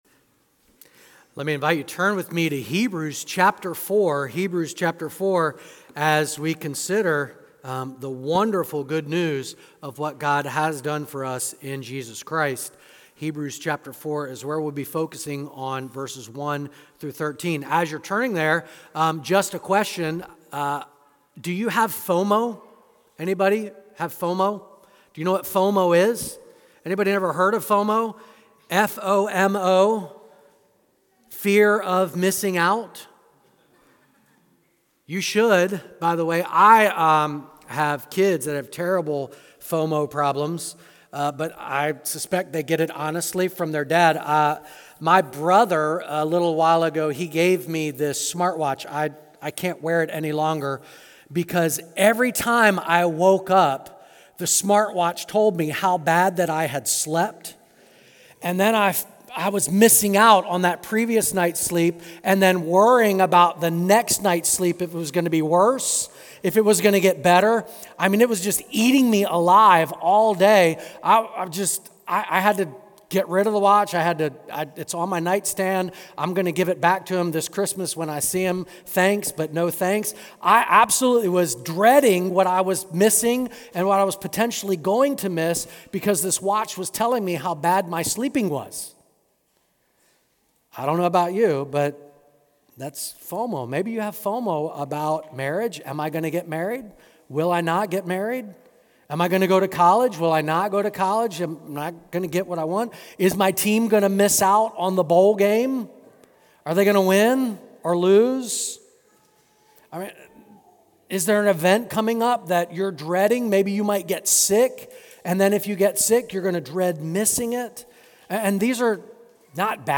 Download Download Christmas 2023 Current Sermon Remember - Rest - Rejoice God Rest Ye Merry Fearful Ones.